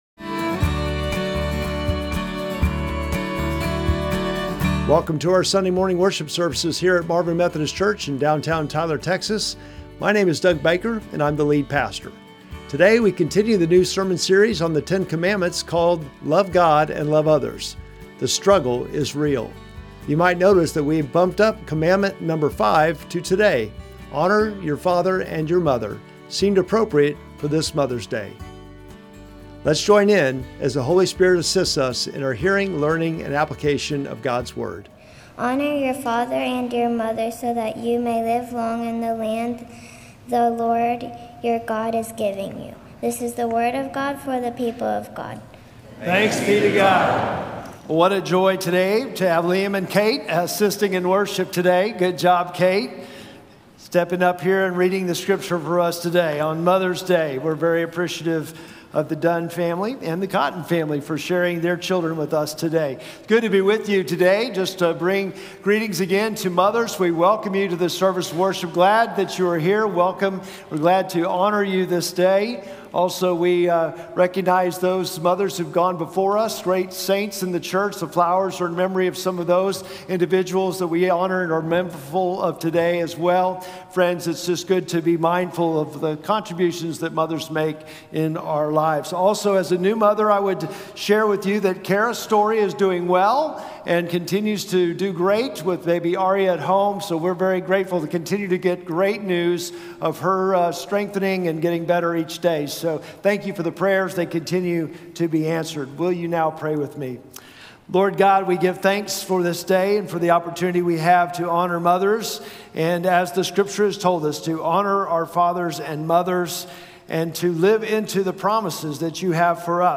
Sermon text: Exodus 20:12